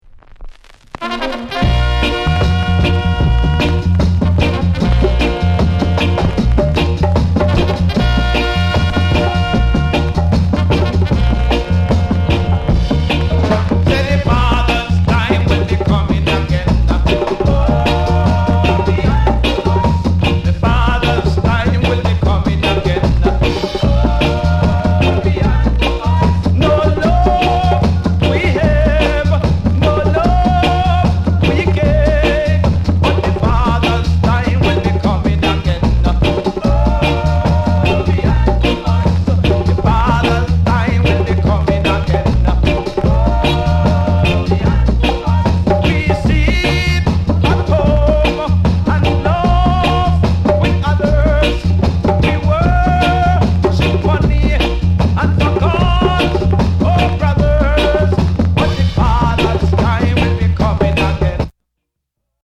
RARE ROCKSTEADY